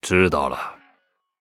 文件 文件历史 文件用途 全域文件用途 Vanjelis_fw_02.ogg （Ogg Vorbis声音文件，长度1.4秒，109 kbps，文件大小：19 KB） 源地址:地下城与勇士游戏语音 文件历史 点击某个日期/时间查看对应时刻的文件。 日期/时间 缩略图 大小 用户 备注 当前 2018年5月13日 (日) 02:56 1.4秒 （19 KB） 地下城与勇士  （ 留言 | 贡献 ） 分类:范哲利斯 分类:地下城与勇士 源地址:地下城与勇士游戏语音 您不可以覆盖此文件。